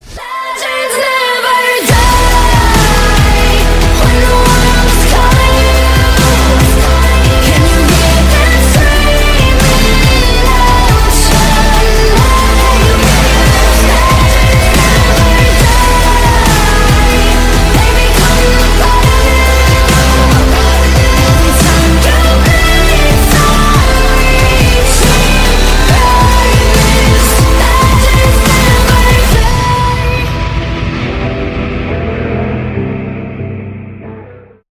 • Качество: 192, Stereo
громкие
мощные
саундтреки
Alternative Rock
Dubstep